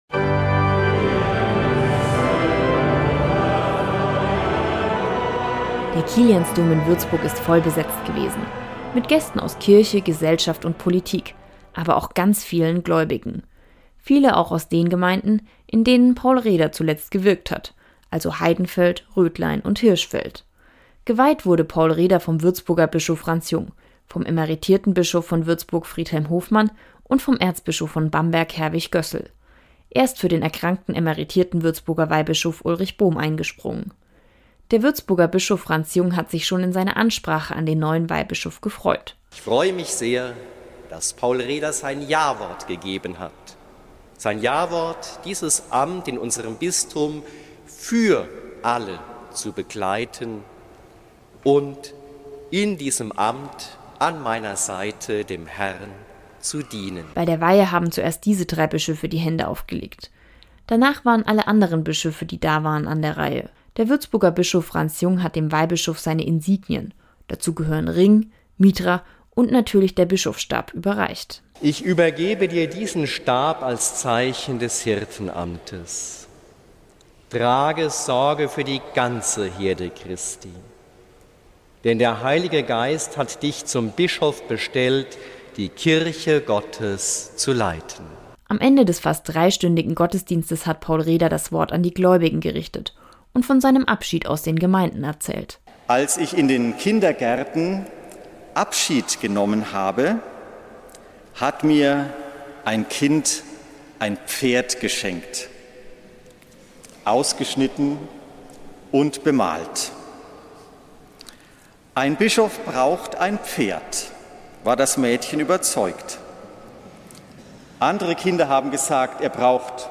Bischofsweihe von Paul Reder